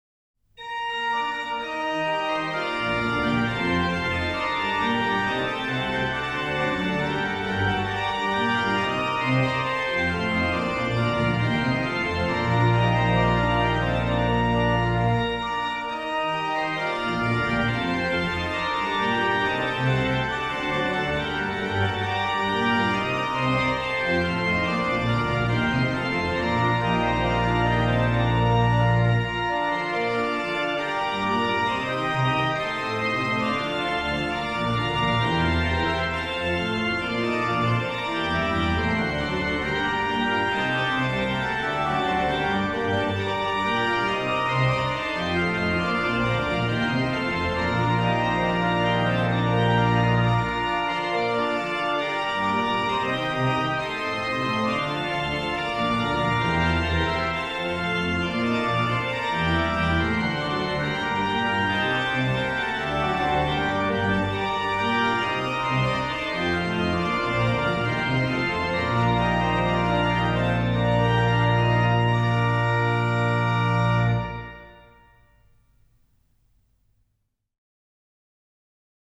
Registration   BW: Ged8, Pr4, Oct2, Mix
Ped: Pr16, Viol16, Oct8, Oct4, Tr8